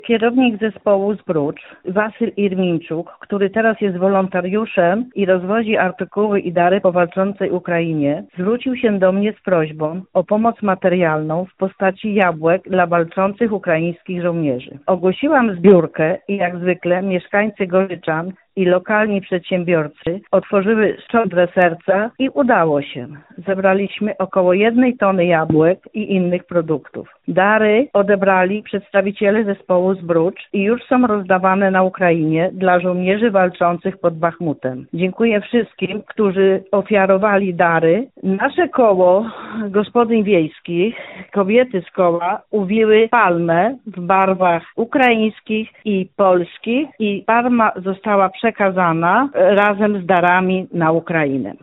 Mieszkańcy wsi Gorzyczany w gminie Samborzec koło Sandomierza pośpieszyli z pomocą dla żołnierzy walczących pod Bachmutem. O szczegółach mówi sołtys wsi Gorzyczany Alicja Wołos: